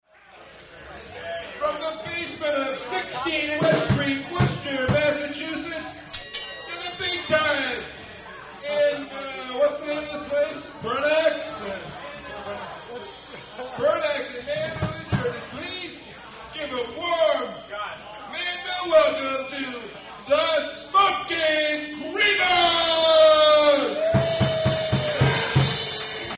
Live in Manville, NJ